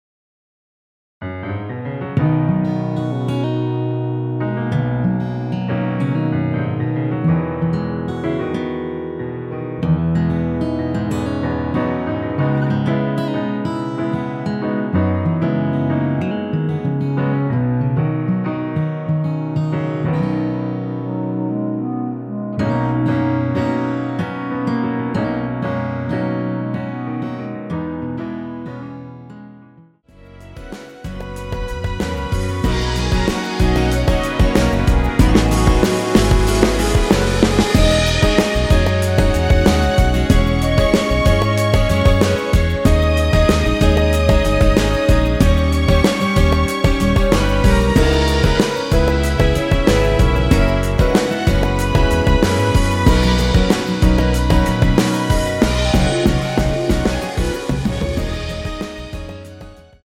원키에서(-2)내린 멜로디 포함된 MR입니다.
앞부분30초, 뒷부분30초씩 편집해서 올려 드리고 있습니다.